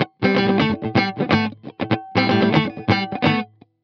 21 GuitarFunky Loop A.wav